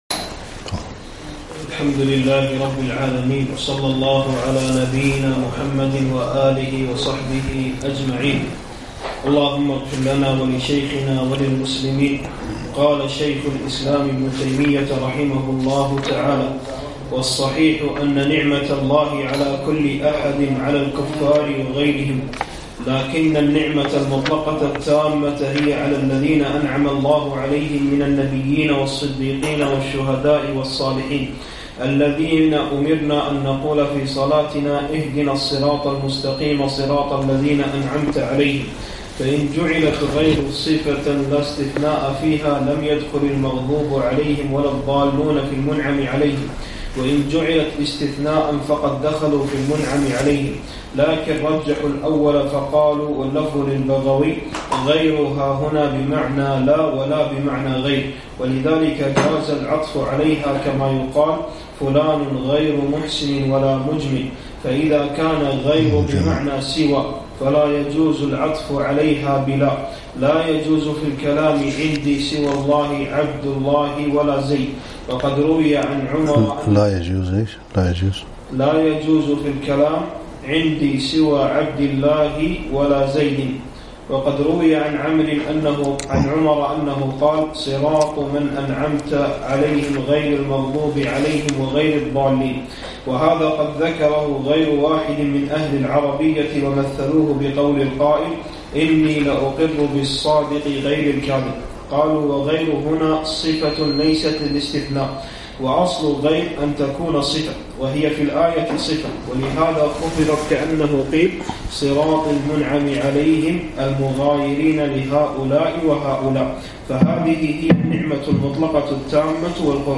(4) تفسير شيخ الإسلام ابن تيمية - الدرس الرابع